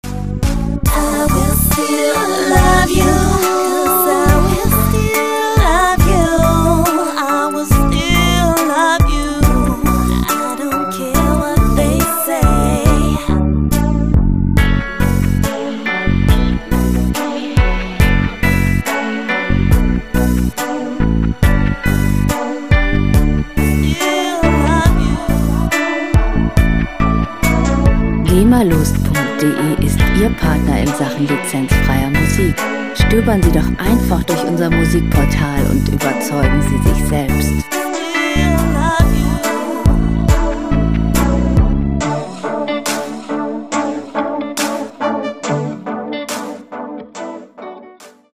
Lounge Musik - Romanzen
Musikstil: Pop Ballad
Tempo: 70 bpm
Tonart: D-Dur
Charakter: ausdrucksstark, sehnsuchtsvoll
Instrumentierung: Gesang, Synthie, Gitarre